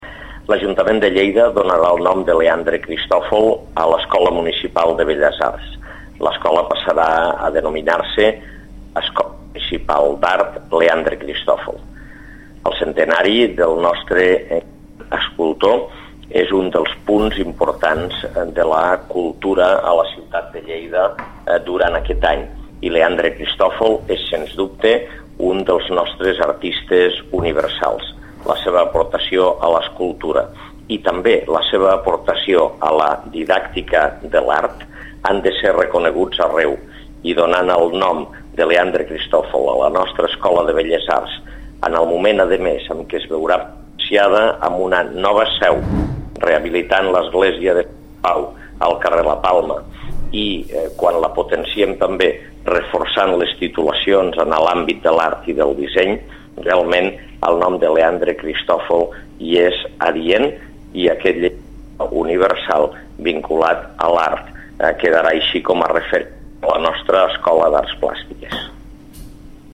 Tall de veu d'Àngel Ros
tall-de-veu-dangel-ros